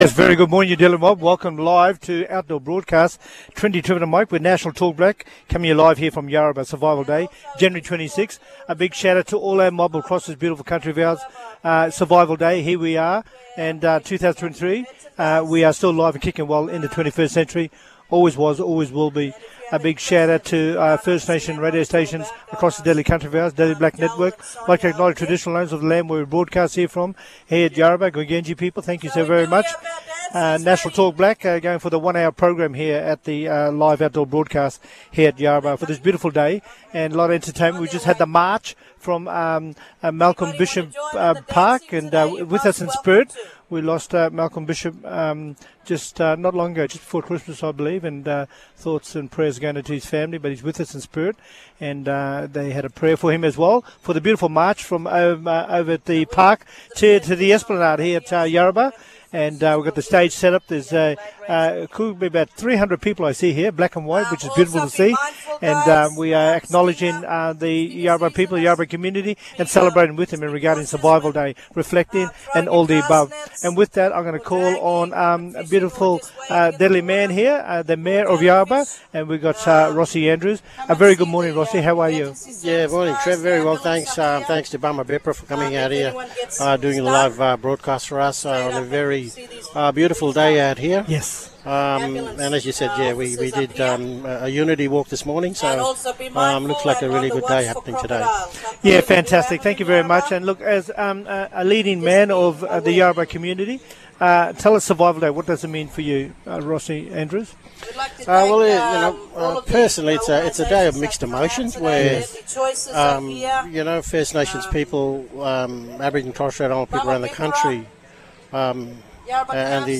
Tune into BBM’s live outdoor broadcast from the Yarrabah Survival Day event 2023!